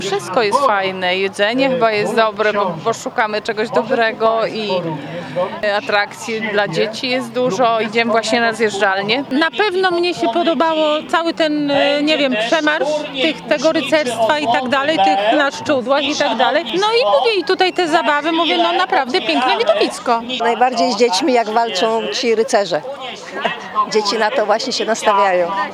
Spytaliśmy mieszkańców gminy Stare Juchy i przyjezdnych, co podoba im się tu najbardziej.